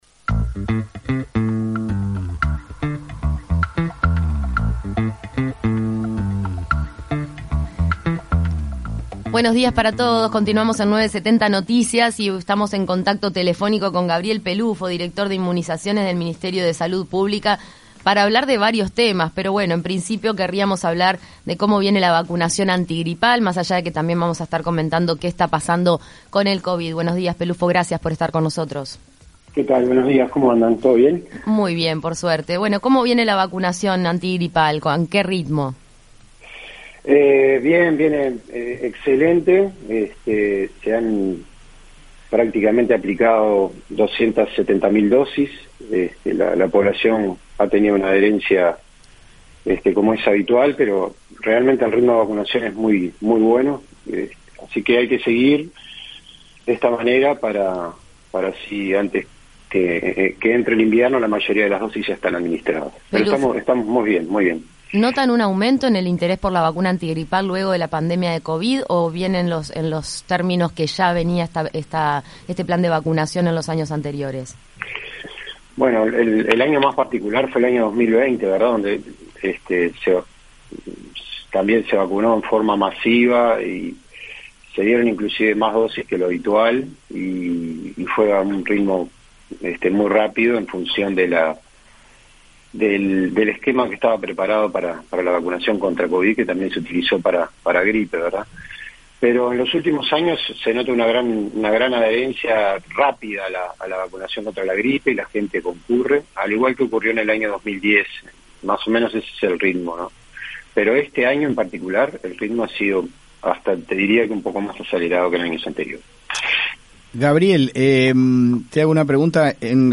En entrevista con 970 Noticias, Primera Edición